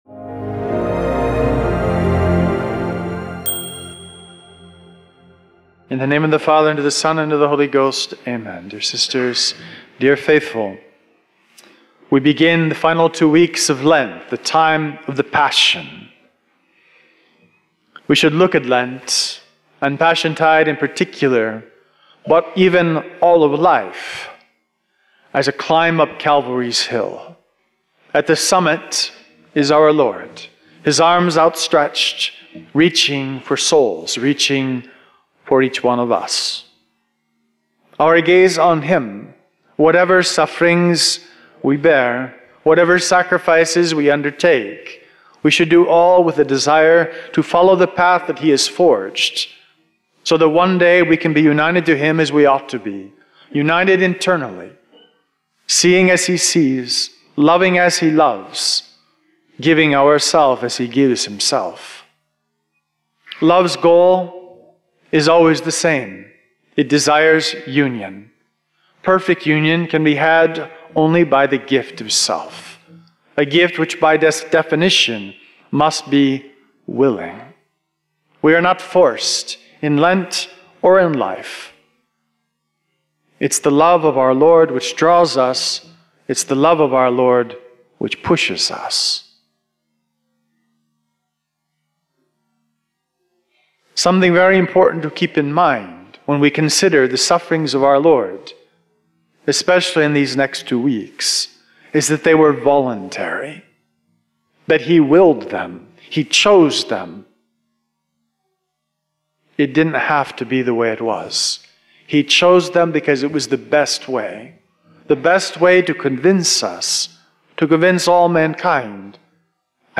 Sermon-73-Audio-converted.mp3